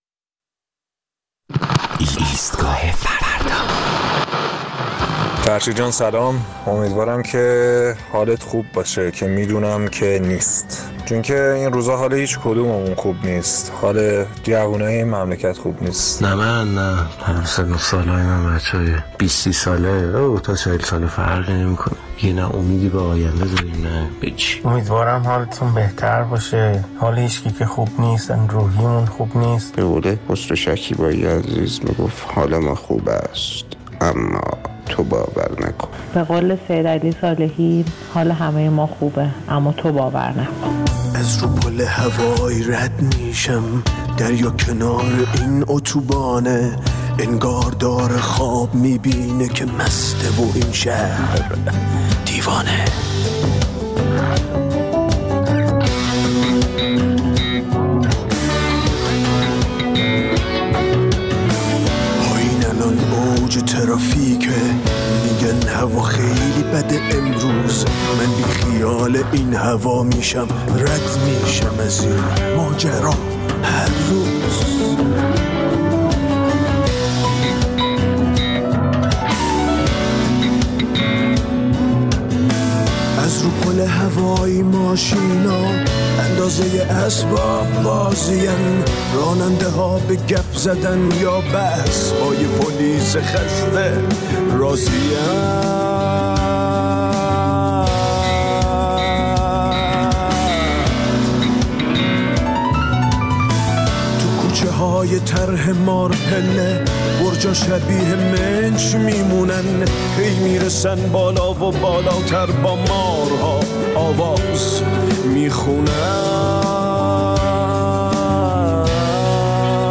در این برنامه ادامه نظرات شنوندگان ایستگاه فردا را در مورد پیامد‌های افزایش قیمت بنزین و درگیری‌ها و اعتراضاتی که در کشور رخ داد، می‌شنویم.